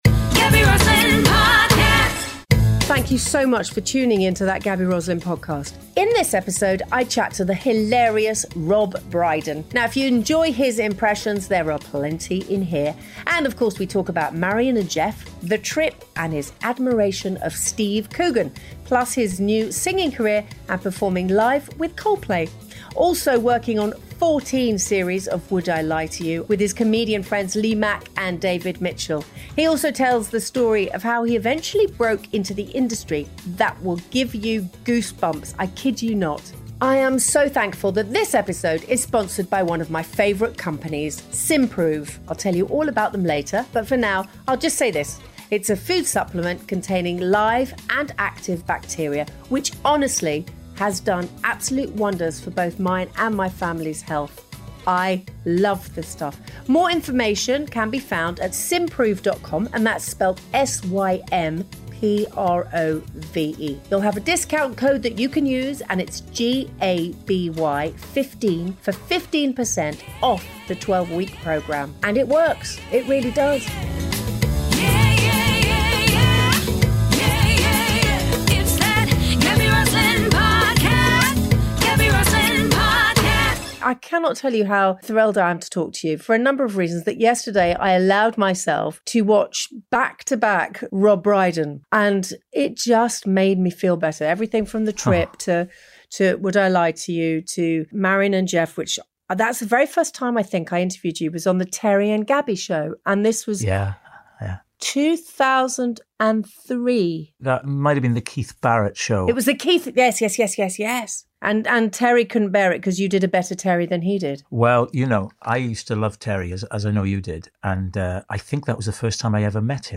In this episode Gaby chats to the hilarious Rob Brydon. He tells the incredible story of how he broke into the entertainment industry.
If you enjoy his impressions, you won’t want to miss this episode!